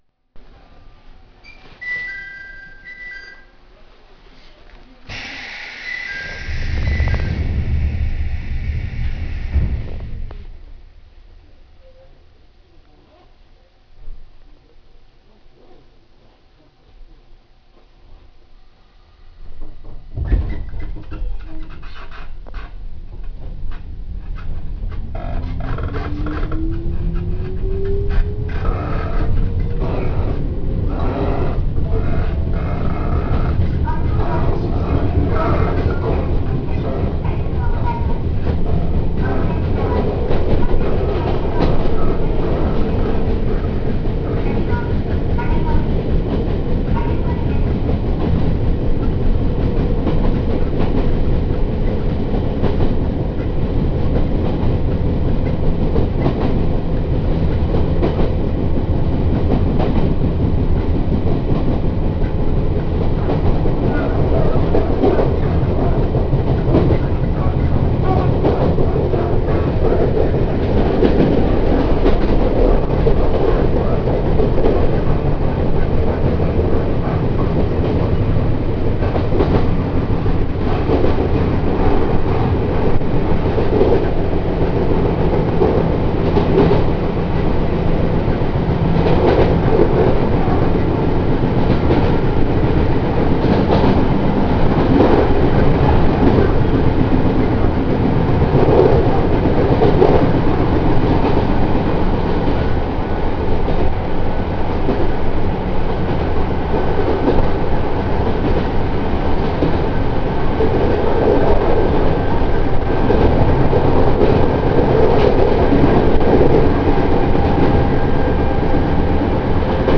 〜車両の音〜
・5000形走行音
【秩父線】御花畑→影森（3分48秒：1.21MB）
ドアチャイムと自動放送が付いたこと以外は三田線時代と見た目も音も何も変わりません。
勿論抵抗制御で、音自体はさして珍しい雰囲気の物ではありません。